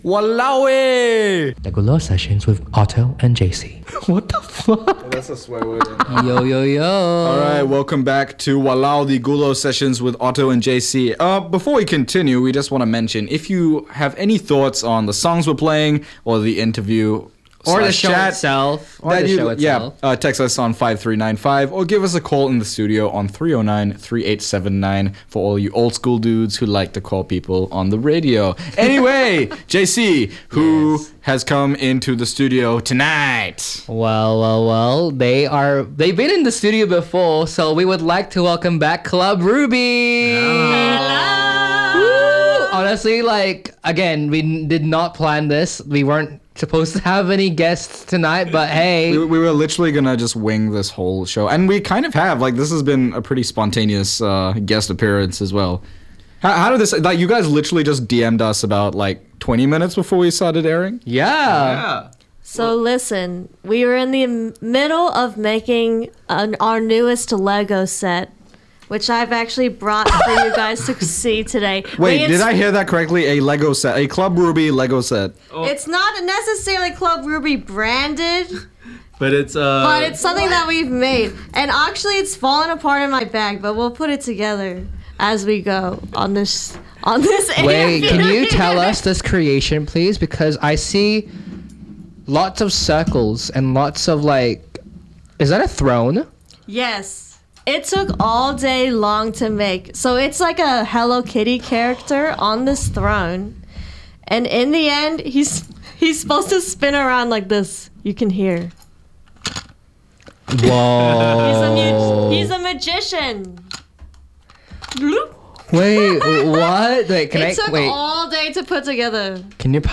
Surprise Interview w Club Ruby.mp3